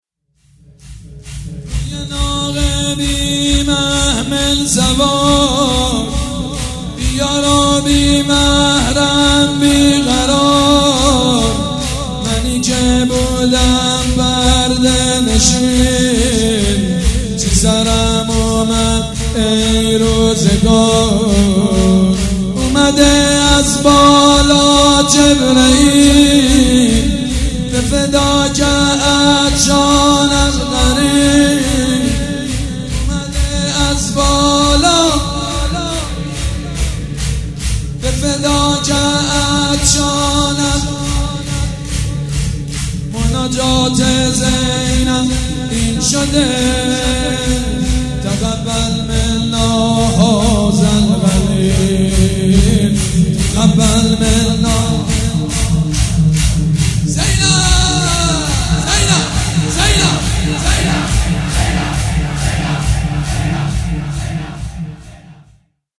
شب یازدهم محرم الحرام‌
شور
مداح
حاج سید مجید بنی فاطمه
مراسم عزاداری شب شام غریبان